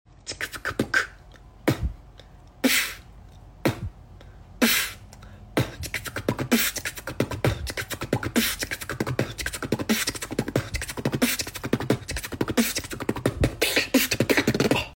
New tutorial for beginner beatboxers!